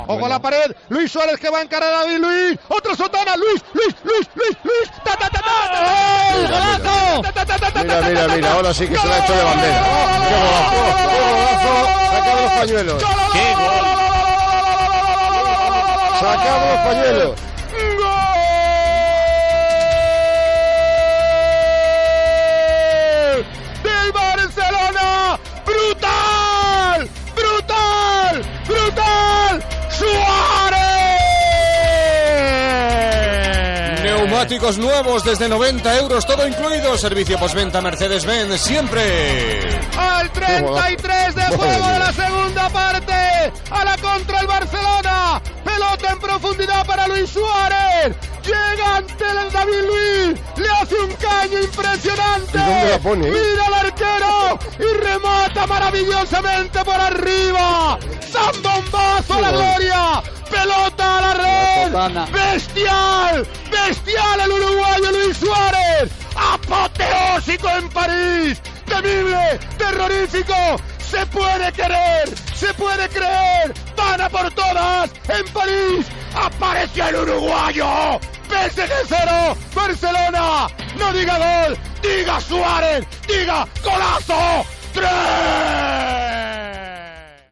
Transmissió del partit Paris Saint Germanin - Futbol Club Barcelona dels quarts de final de la Copa d'Europa de futbol masculí.
Esportiu